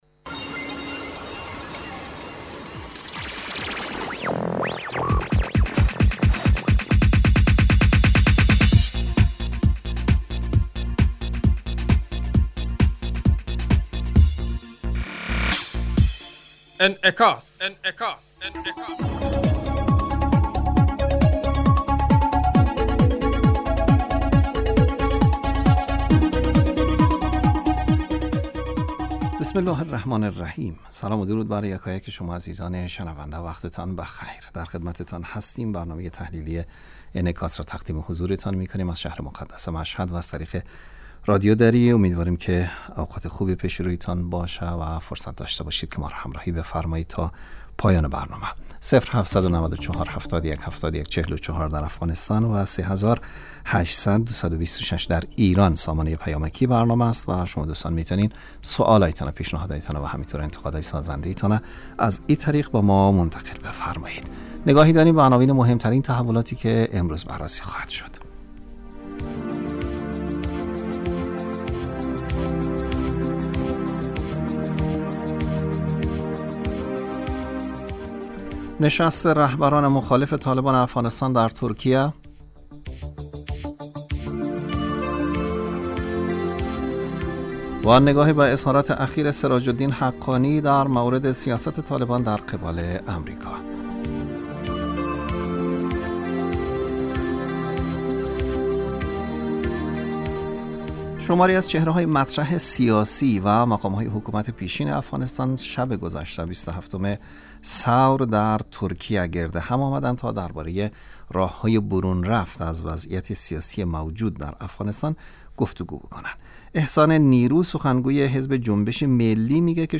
برنامه انعکاس به مدت 30 دقیقه هر روز در ساعت 18:40 بعد ظهر بصورت زنده پخش می شود. این برنامه به انعکاس رویدادهای سیاسی، فرهنگی، اقتصادی و اجتماعی مربوط به افغانستان و تحلیل این رویدادها می پردازد.